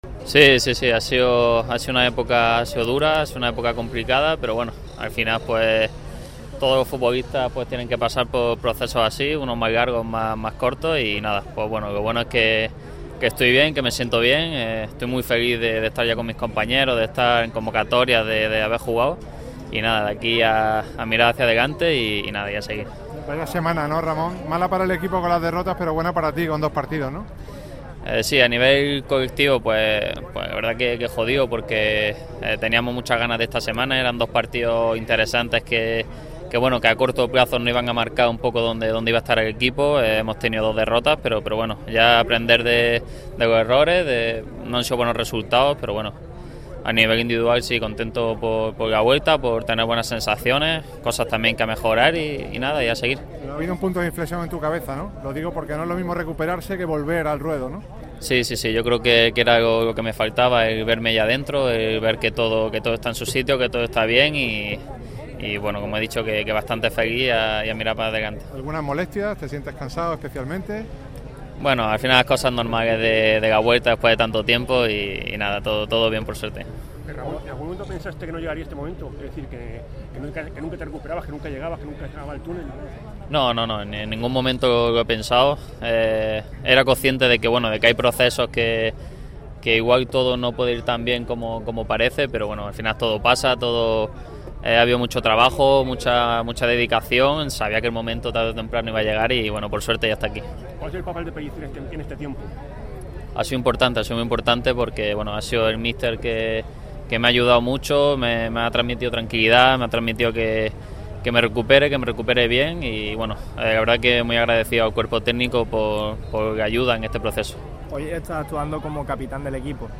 El centrocampista ejerce de capitán y responde preguntas de la prensa.
El jugador malaguista ha atendido a la prensa con motivo de la Gran Feria Sabor a Málaga 2024. Sin pelos en la lengua, Ramón habla sobre la dinámica del equipo, objetivos, su proceso personal, Pellicer y su nuevo rol como capitán del Málaga CF.